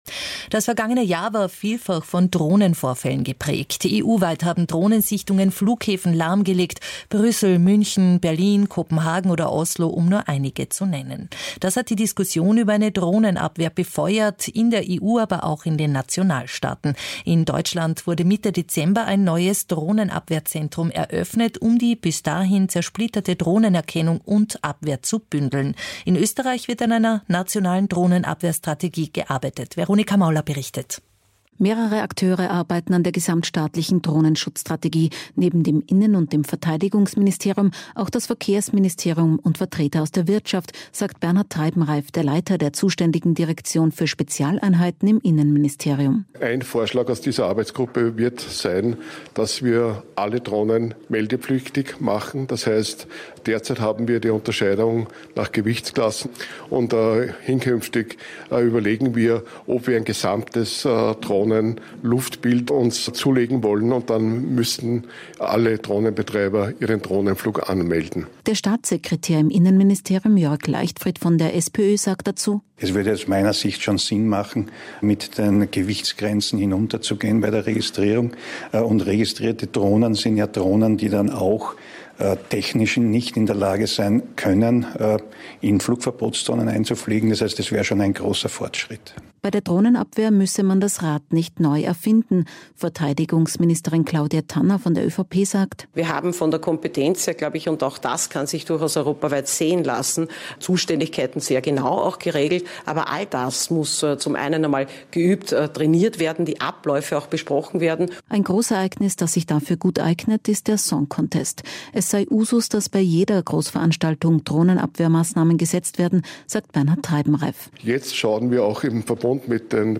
Hier findest Du den entsprechenden Ö1 Radio Beitrag zur Drohnenabwehr Strategie in Österreich, aus dem Ö1 Mittagsjournal vom 12. Februar 2026.